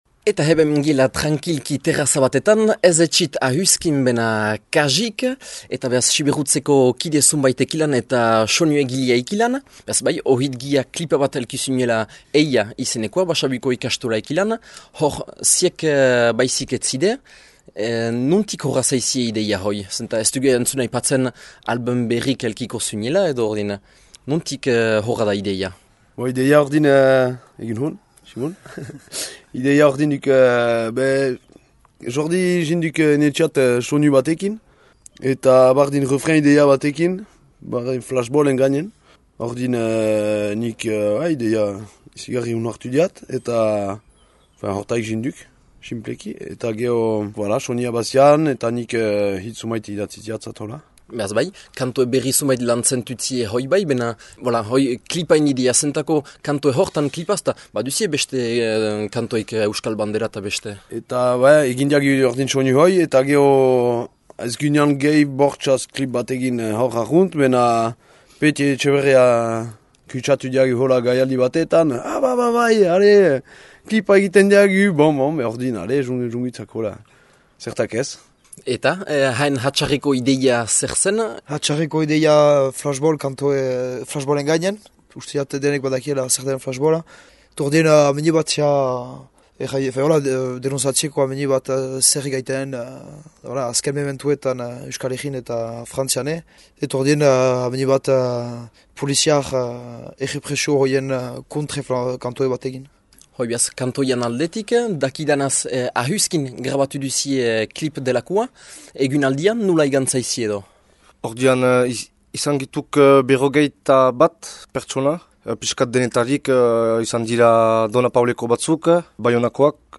Bena beste hainbat lagüntzaleek ere parte hartü düe, nork eta nola iragan den jakiteko beha alkarrizketa: